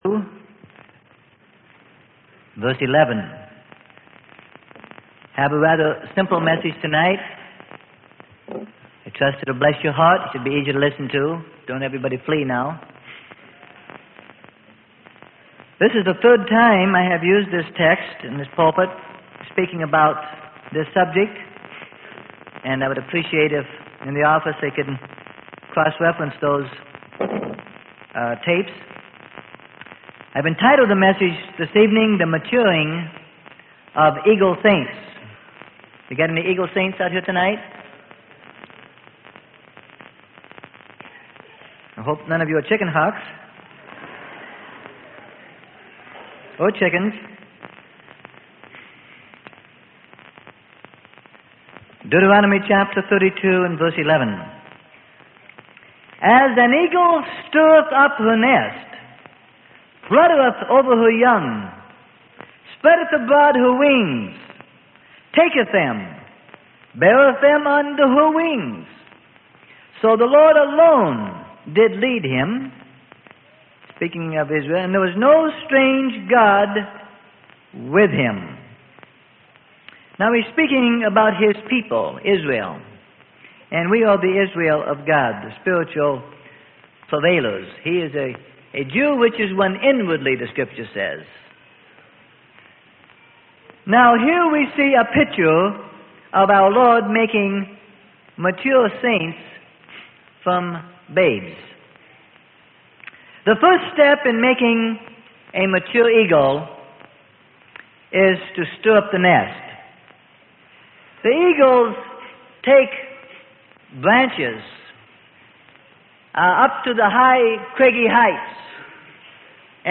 Sermon: The Maturing Of Eagle Saints.